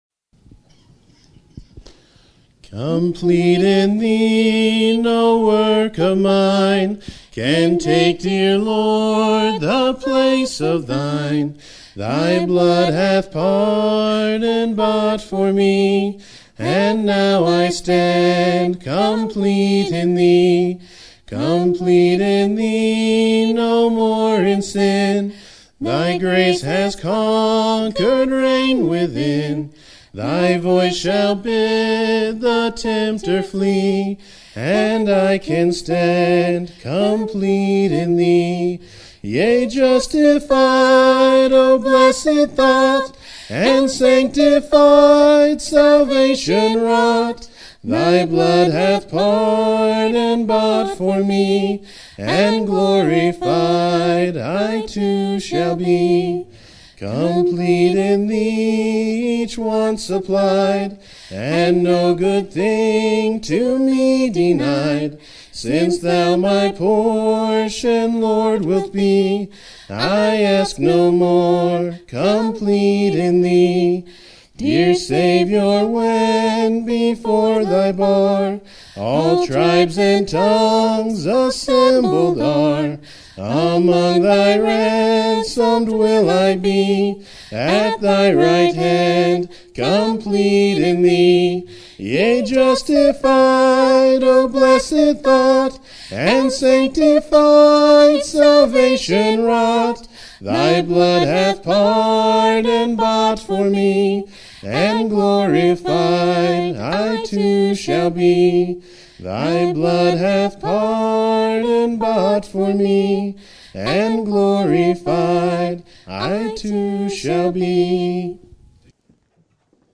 Sermon Topic: General Sermon Type: Service Sermon Audio: Sermon download: Download (31.14 MB) Sermon Tags: Isaiah Gardener Thorn Holy